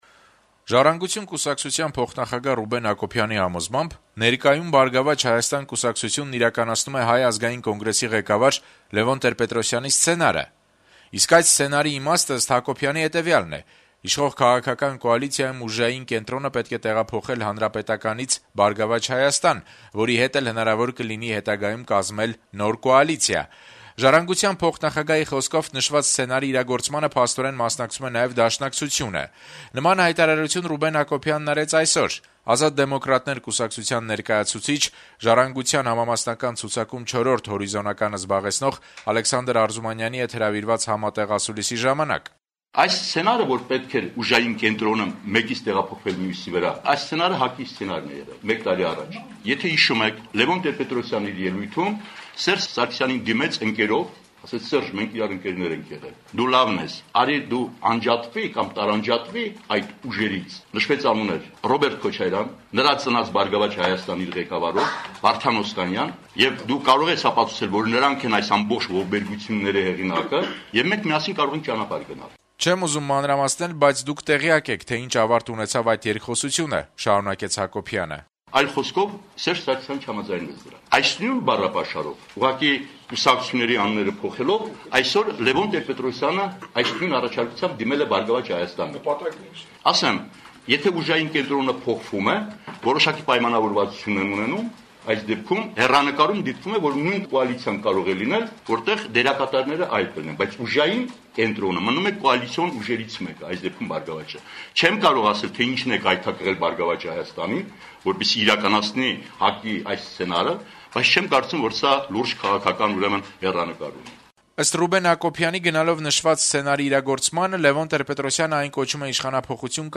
«Եթե հիշում եք` Լեւոն Տեր-Պետրոսյանը իր ելույթում Սերժ Սարգսյանին դիմեց «ընկերով», ասաց` Սերժ, մենք ընկերներ ենք եղել, դու լավն ես, արի, դու անջատվի կամ տարանջատվի այդ ուժերից… անուններ էլ նշվեց` Ռոբերտ Քոչարյան, նրա ծնած «Բարգավաճ Հայաստան»-ը իր ղեկավարով, Վարդան Օսկանյան… ասաց, դու կարող ես ապացուցել, որ նրանք են այս ամբողջ ողբերգությունների հեղինակը, եւ մենք միասին կարող ենք ճանապարհ գնալ», - հայտարարեց Հակոբյանը երկուշաբթի օրը «Ազատ դեմոկրատներ» կուսակցության ներկայացուցիչ, «Ժառանգության» համամասնական ցուցակում 4-րդ հորիզոնականը զբաղեցնող Ալեքսանդր Արզումանյանի հետ հրավիրած համատեղ ասուլիսի ժամանակ: